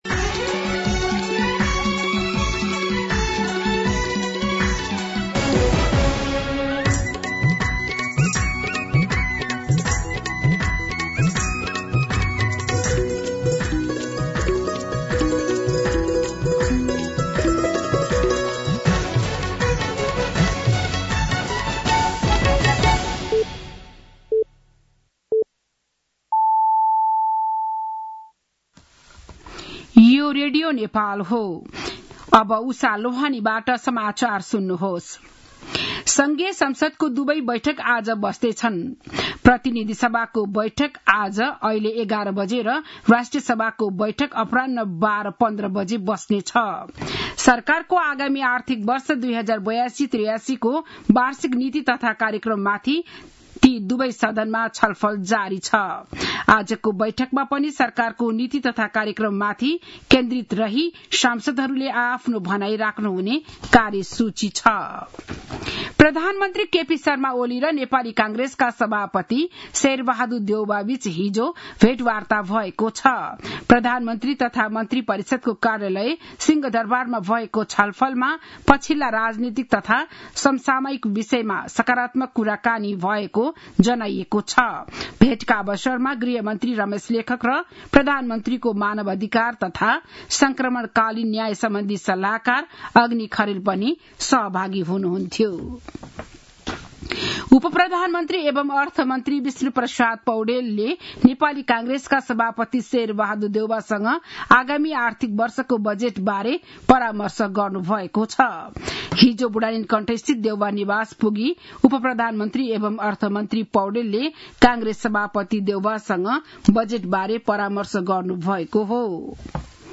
बिहान ११ बजेको नेपाली समाचार : २३ वैशाख , २०८२
11am-Nepai-News-23.mp3